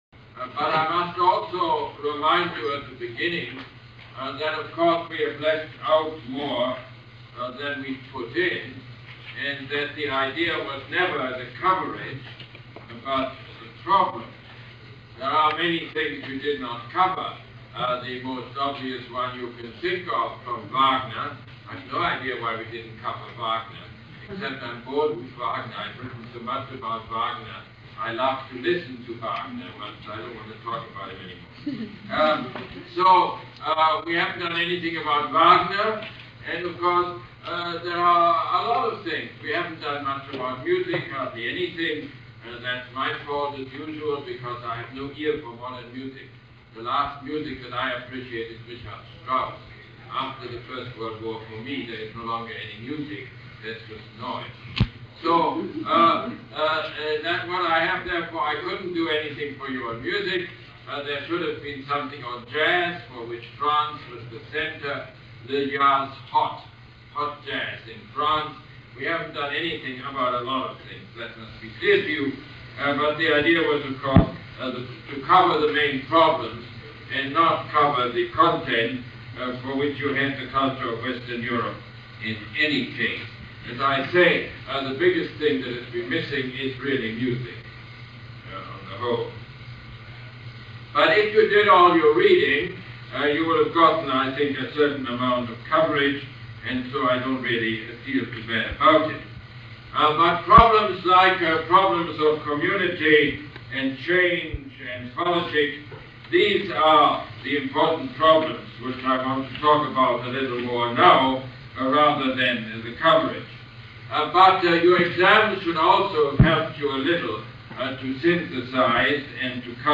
Lecture #30 - December 12, 1979